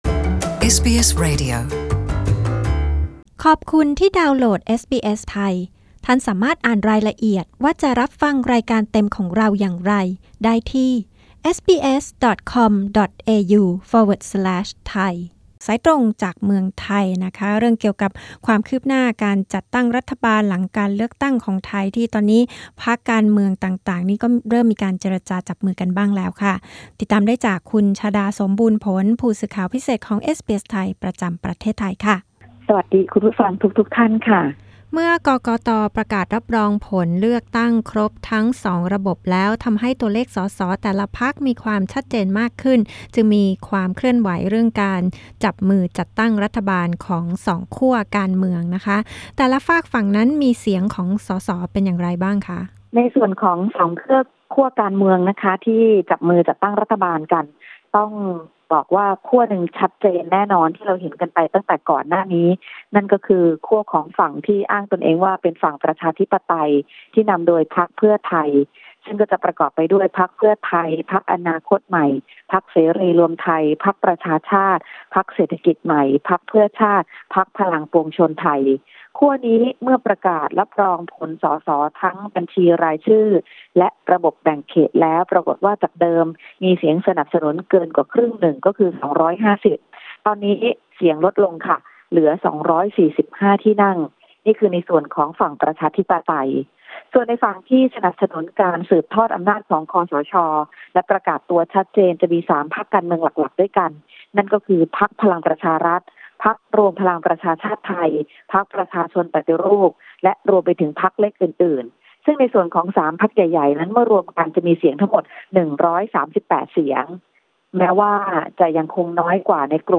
รายงานนี้ออกอากาศเมื่อคืนวันพฤหัสบดี ที่ 9 พ.ค. ในรายการวิทยุเอสบีเอส ไทย เวลา 22.00 น.